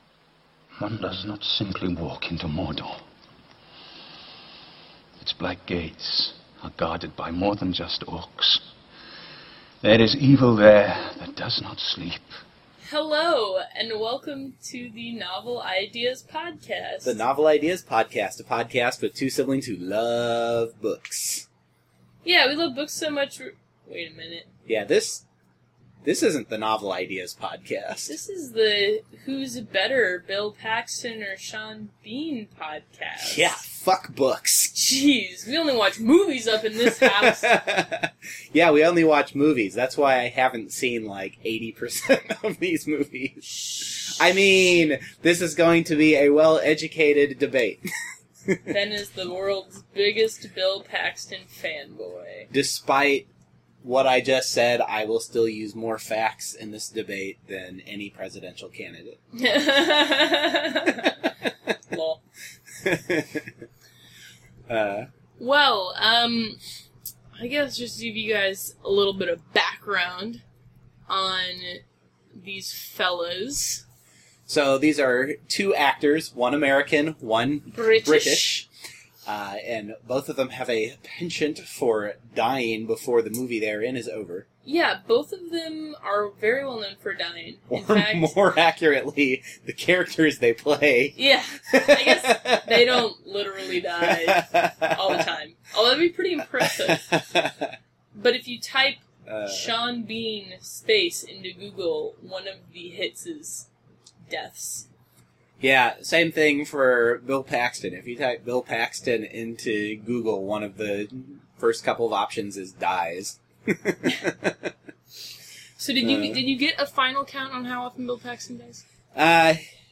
In this episode we delve into topics that are somewhat outside of our area of expertise, with an especially high level of broadcast professionalism. If we sound kind of strange, it’s because we left the fan on in the room where we recorded and scrubbed the noise out using the finest available free software.